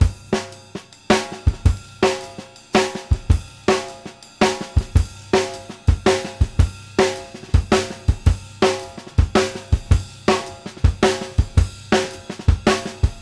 CYM FEEL  -L.wav